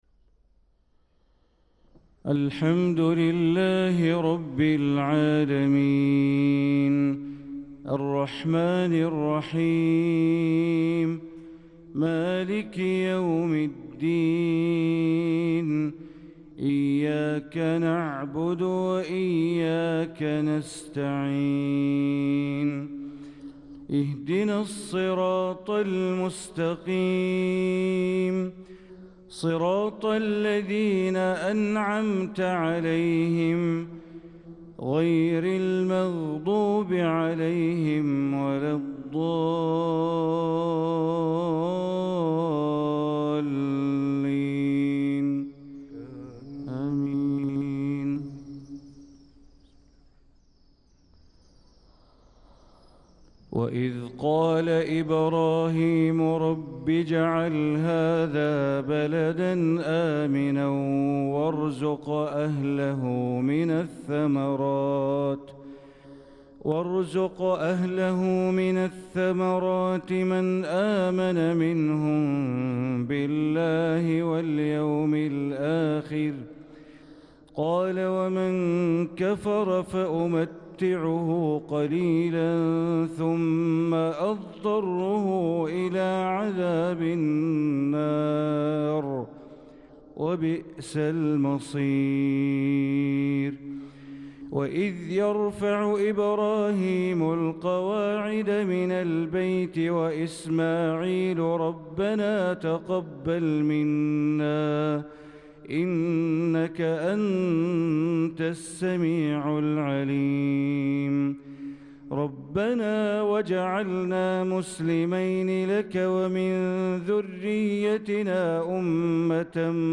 صلاة الفجر للقارئ بندر بليلة 19 ذو القعدة 1445 هـ
تِلَاوَات الْحَرَمَيْن .